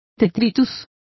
Complete with pronunciation of the translation of detritus.